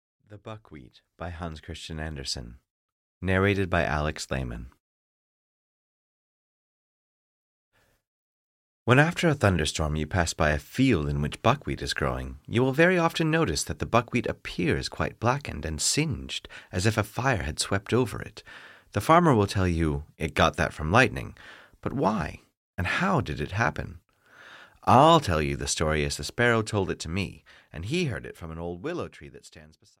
The Buckwheat (EN) audiokniha
Ukázka z knihy